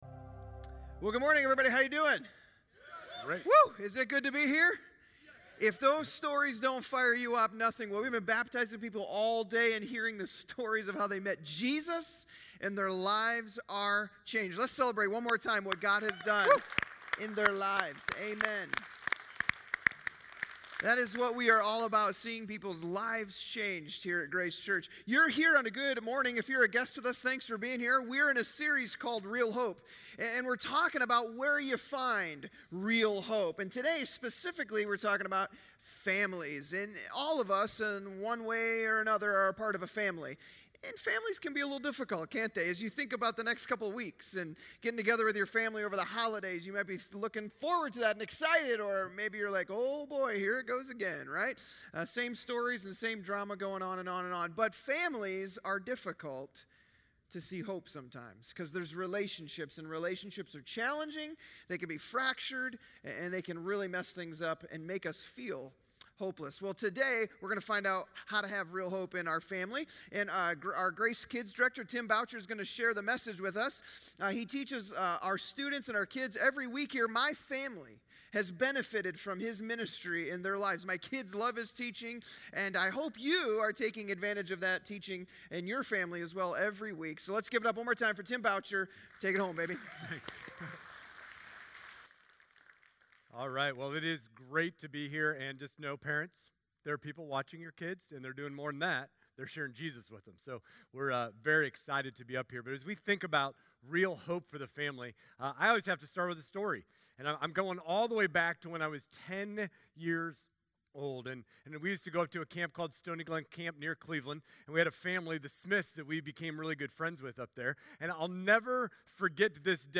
11.13_Sermon_Audio.mp3